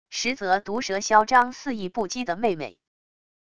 实则毒舌嚣张肆意不羁的妹妹wav音频